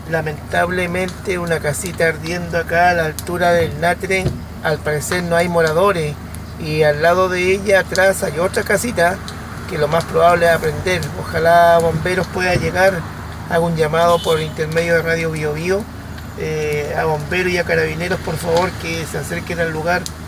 Específicamente, en el sector El Natre, a unos 5 kilómetros desde Cajón a Vilcún, tal como uno de los auditores de Radio Bío Bío lo narró al momento de pasar por el lugar.